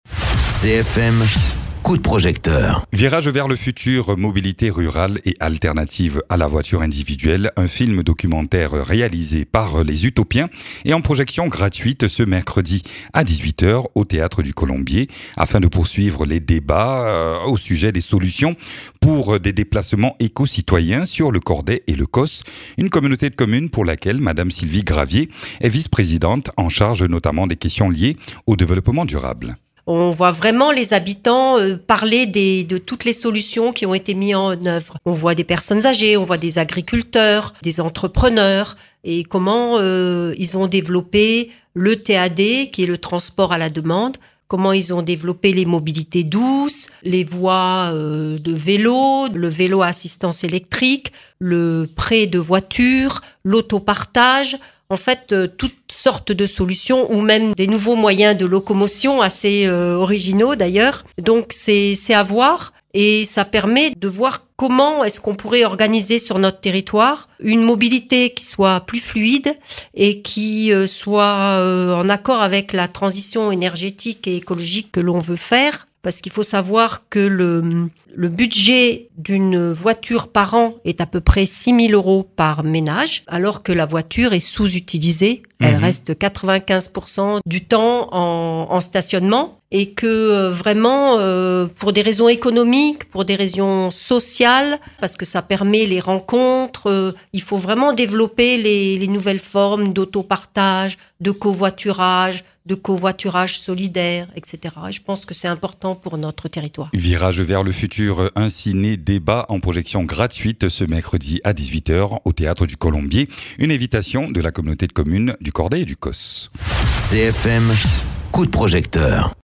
Interviews
Invité(s) : Sylvie Gravier, 3ème vice-présidente de la communauté de communes du Cordais et du Causse en charge du développement durable